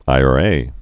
(īär-ā)